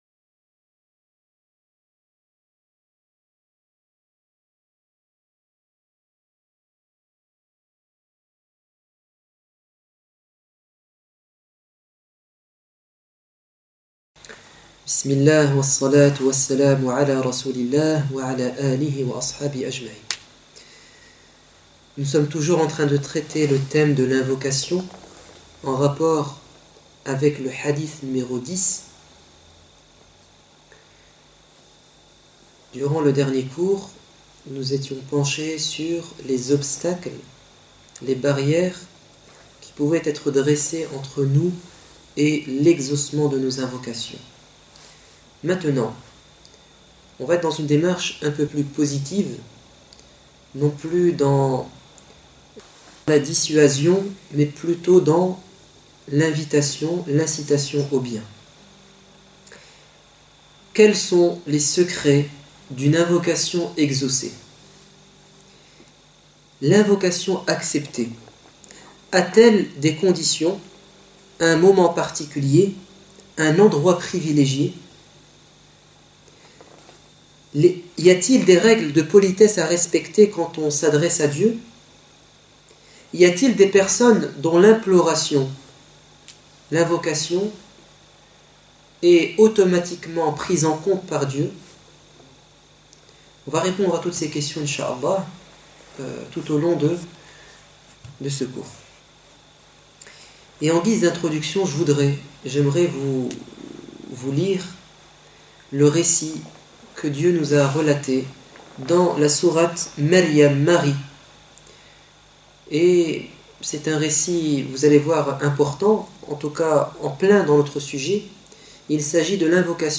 Vidéo et audio d'éducation islamique à la mosquée de Raismes